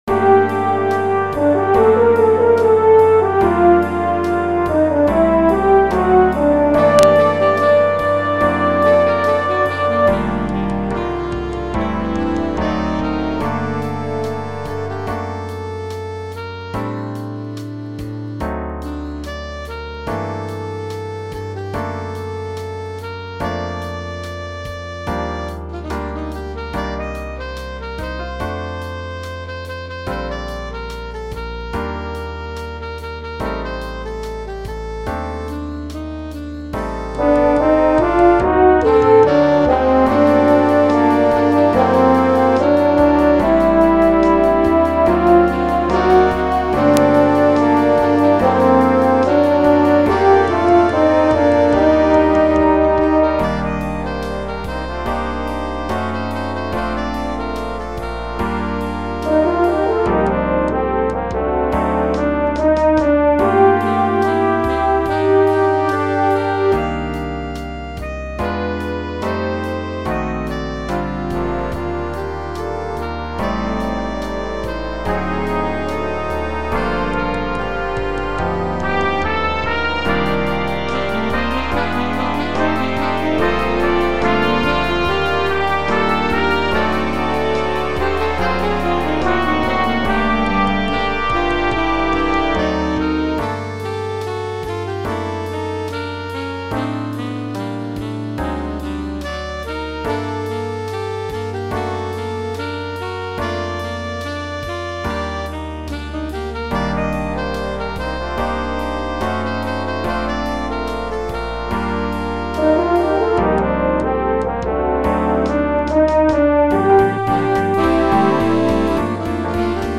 Style: Ballad
Instrumentation: Standard Jazz Band